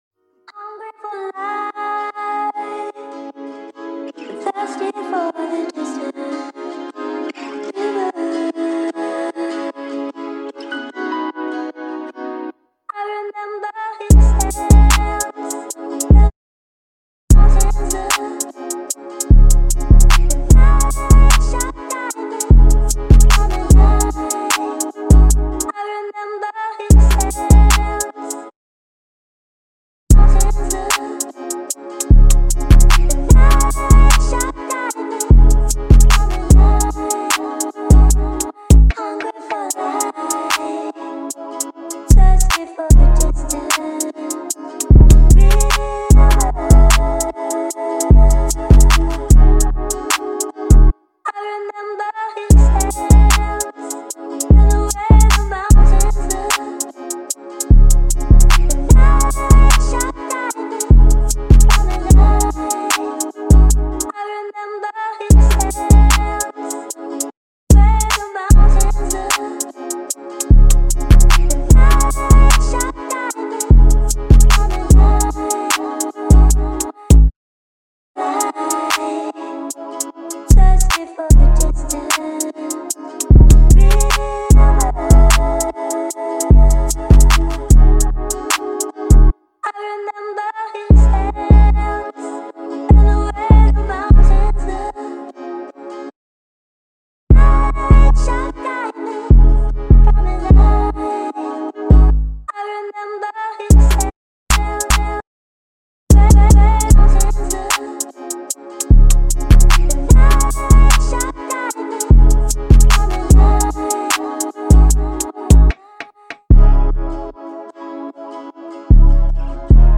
official instrumental
2024 in Hip-Hop Instrumentals